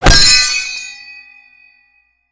sound effect unique to this weapon, the Golden Wrench, and the Saxxy is played upon killing any enemy.
Saxxy_impact_gen_06.wav